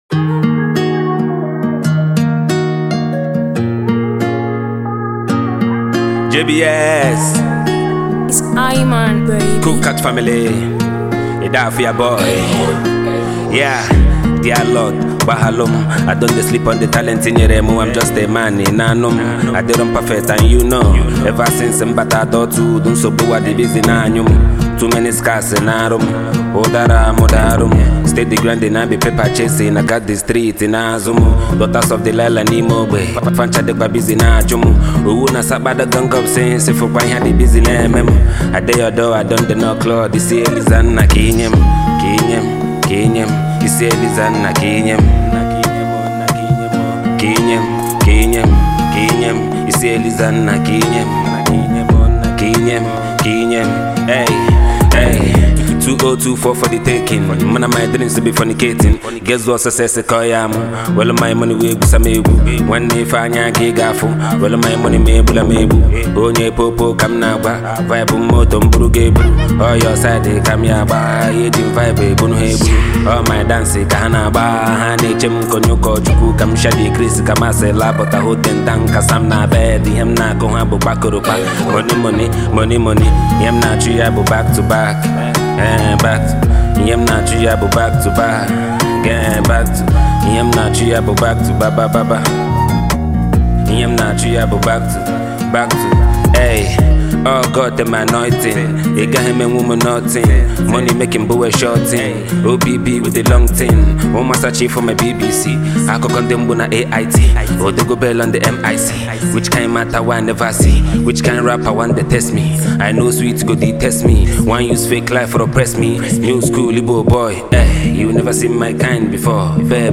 Afro-drill